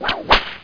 whip.mp3